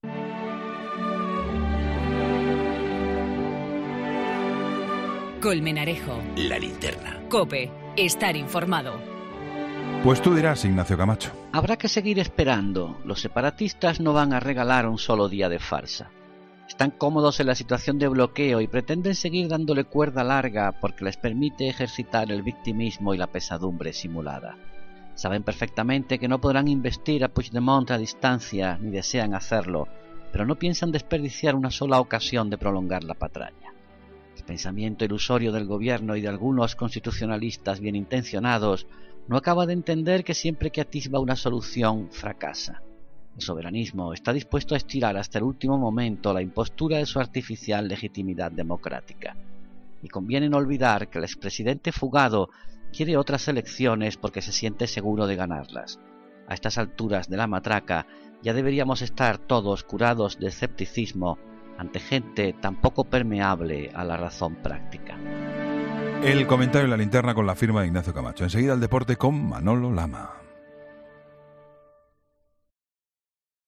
Ignacio Camacho habla en 'La Linterna' sobre la situación en Cataluña tras la aprobación en el Parlament de la ley para investir a Puigdemont presidente